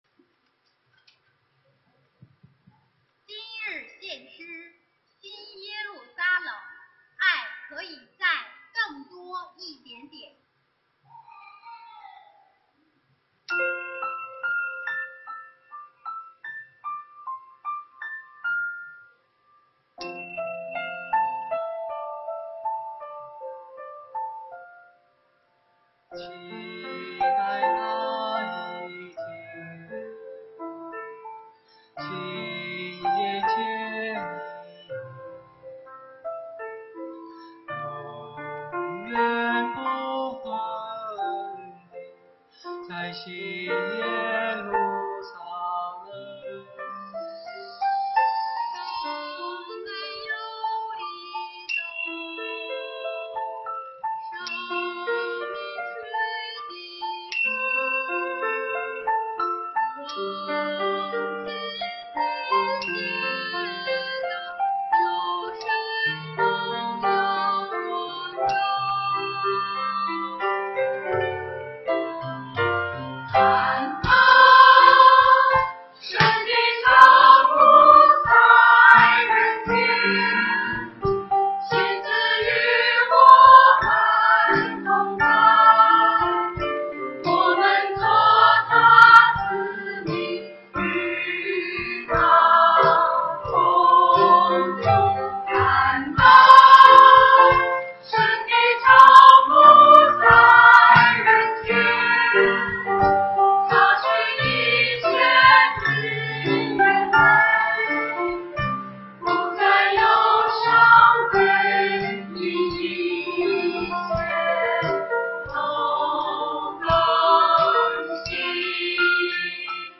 中秋赞美会
团契名称: 联合诗班 新闻分类: 诗班献诗